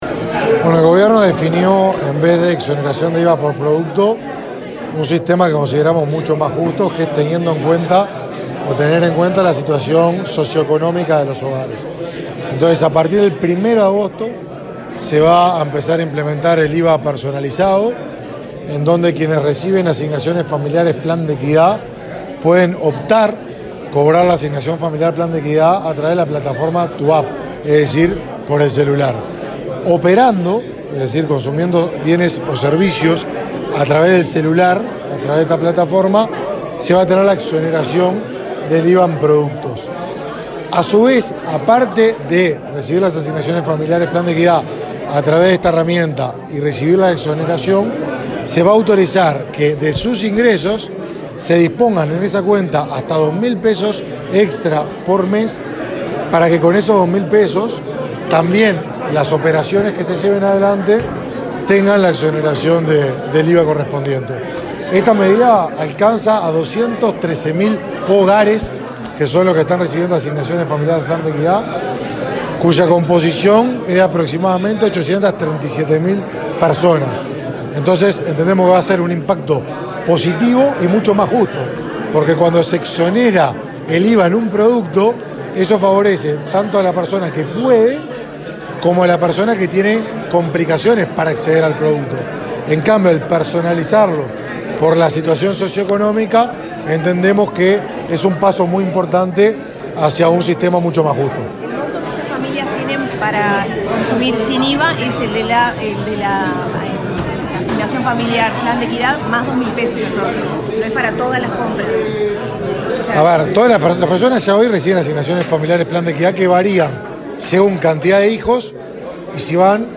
Declaraciones a la prensa del ministro de Desarrollo Social, Martín Lema
Declaraciones a la prensa del ministro de Desarrollo Social, Martín Lema 12/07/2022 Compartir Facebook X Copiar enlace WhatsApp LinkedIn El ministro de Desarrollo Social, Martín Lema, encabezó una conferencia realizada para presentar el impuesto al valor agregado (IVA) personalizado, una herramienta destinada a la población vulnerable. Luego, dialogó con la prensa.